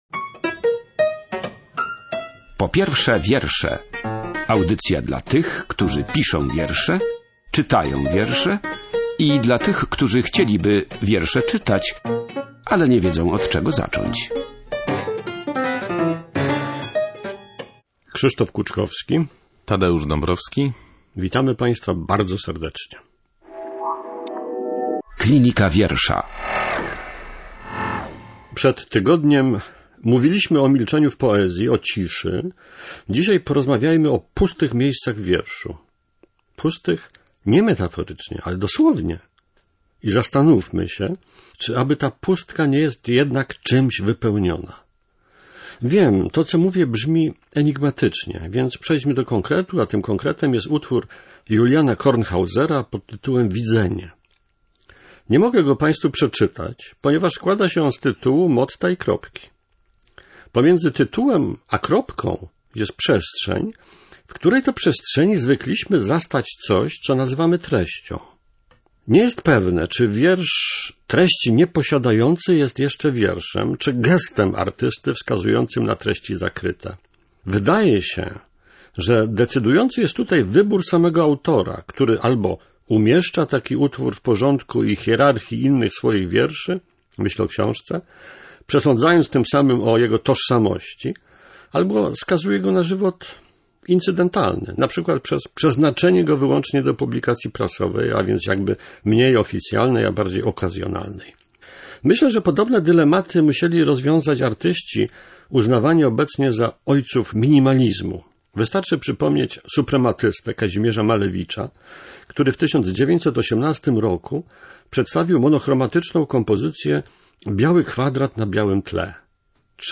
Na ten temat w audycji Po pierwsze wiersze dyskutowali